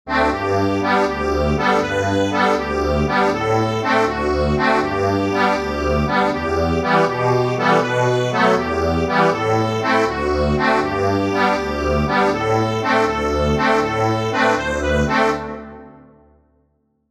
Instrument: accordion
This lively tune, in 6/8 time, could certainly be danced to.